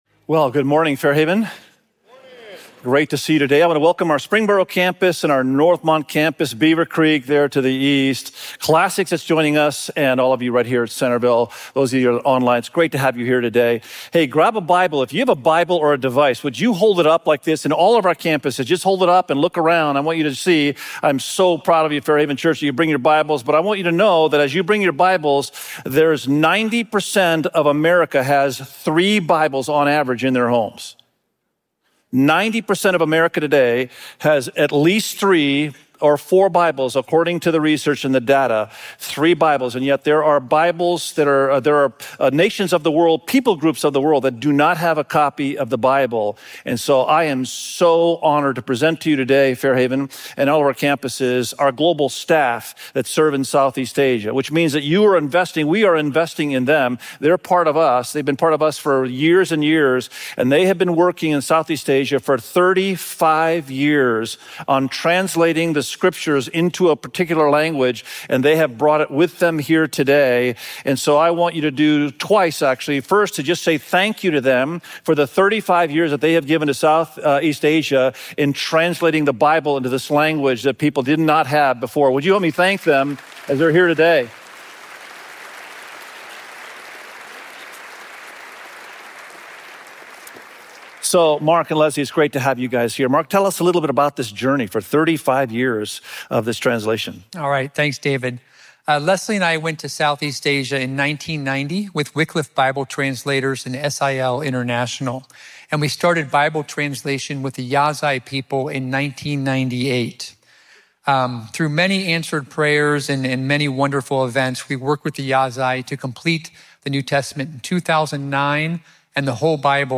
Its-all-Yours_SERMON.mp3